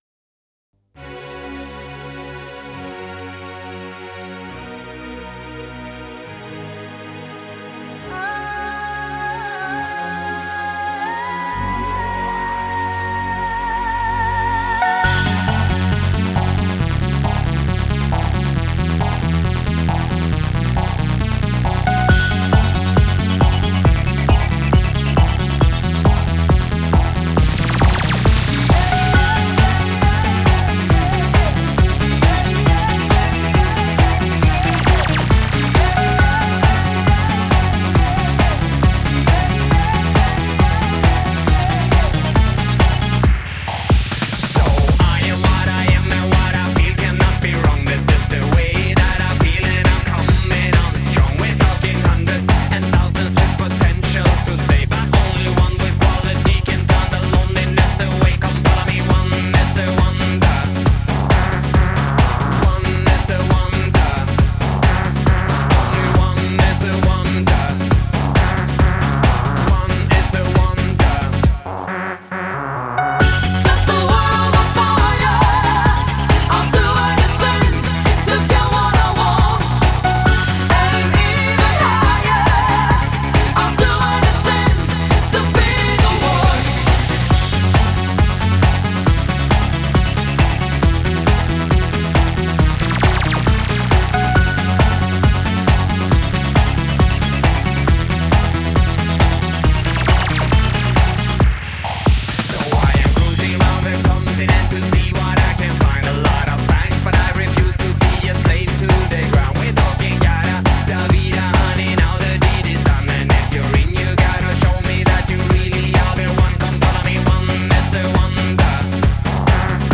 Жанр: Eurodance, Pop